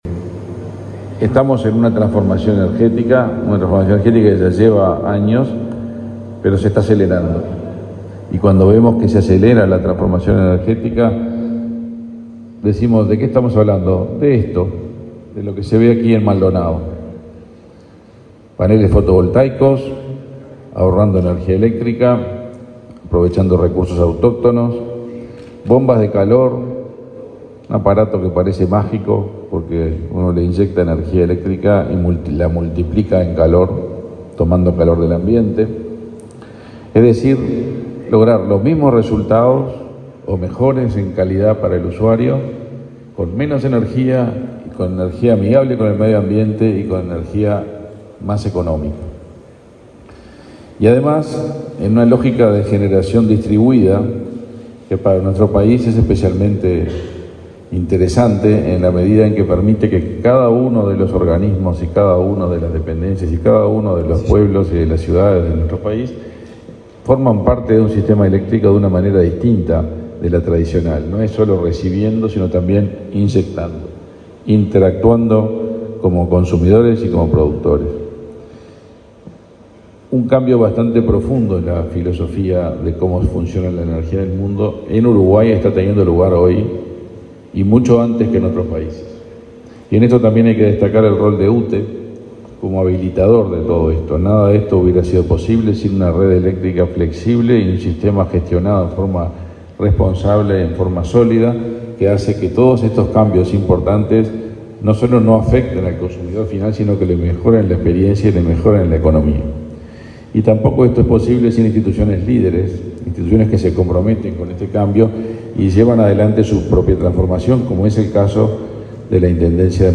Palabras de autoridades en inauguración de plantas fotovoltaicas
Palabras de autoridades en inauguración de plantas fotovoltaicas 04/08/2023 Compartir Facebook X Copiar enlace WhatsApp LinkedIn El ministro de Industria, Omar Paganini, y la presidenta de UTE, Silvia Emaldi, participaron en el acto de inauguración de 10 plantas fotovoltaicas y bombas de calor en el departamento de Maldonado.